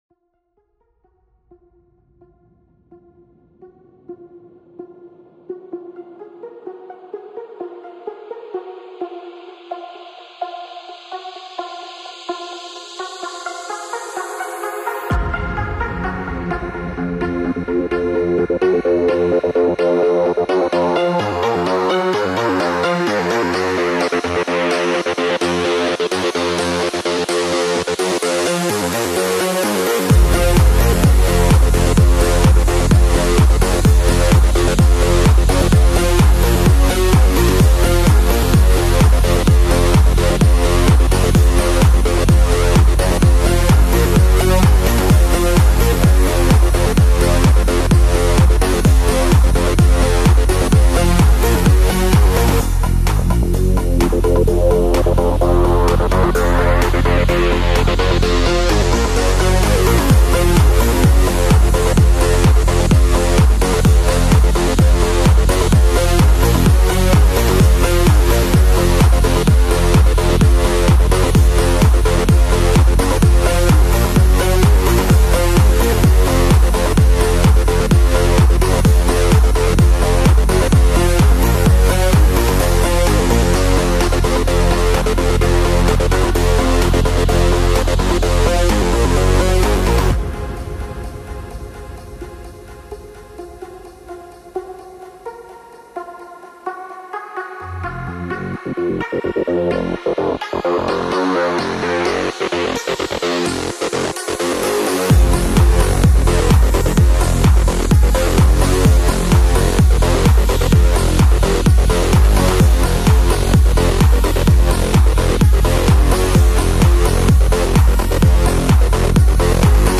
Industrial Music : RAVAGE mp3 format.